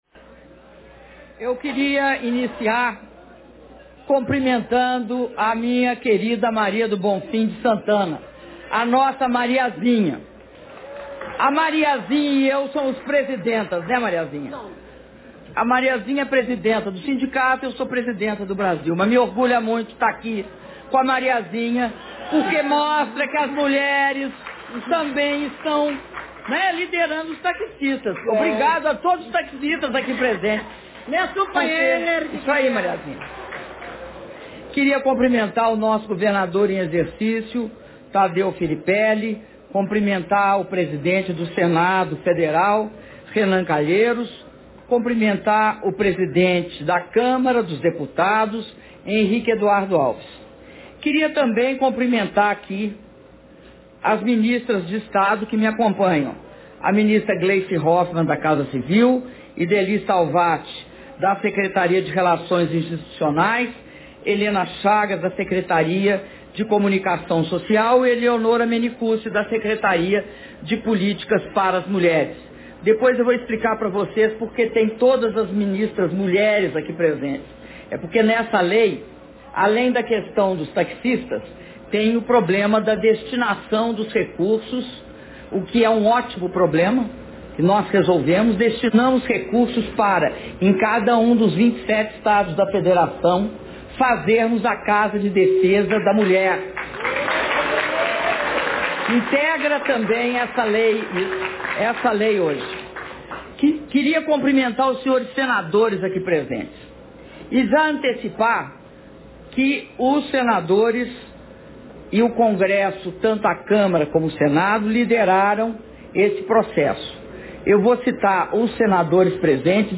Áudio do discurso da Presidenta da República, Dilma Rousseff, na cerimônia de sanção da MP 615 - Brasília/DF (12min29s)